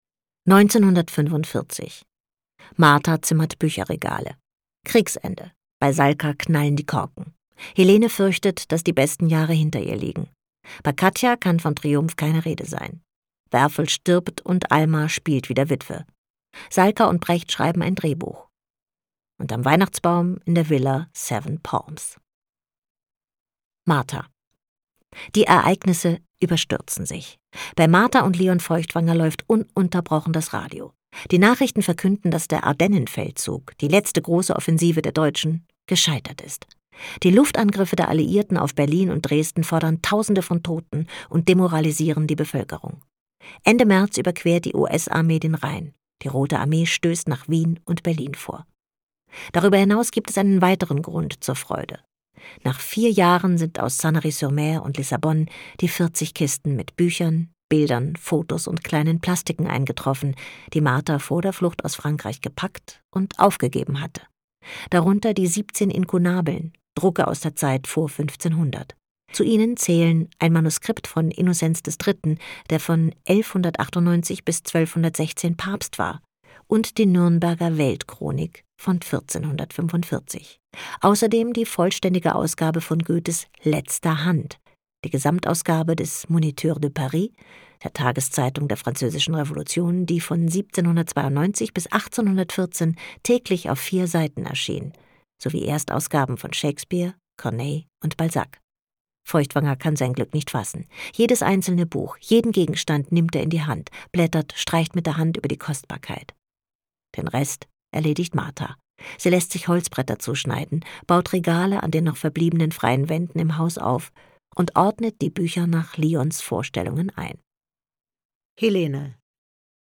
Hörbücher vom Montalto Verità Verlag